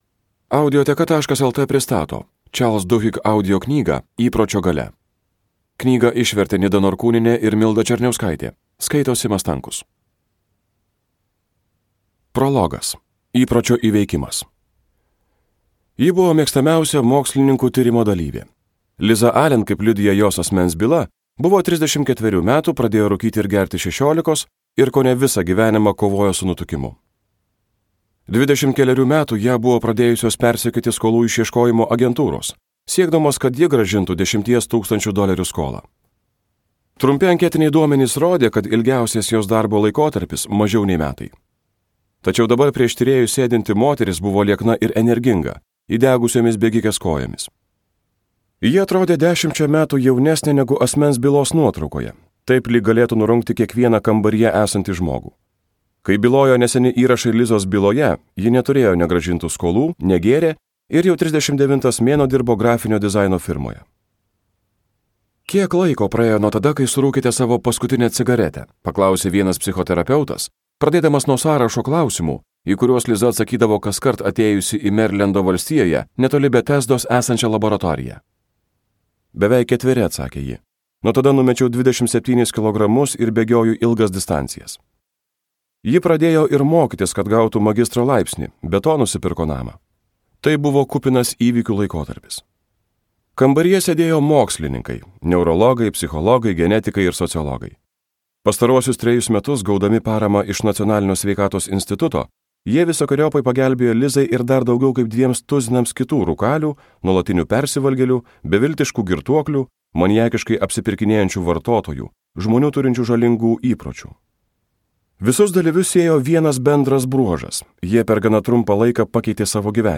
Skaityti ištrauką play 00:00 Share on Facebook Share on Twitter Share on Pinterest Audio Įpročio galia.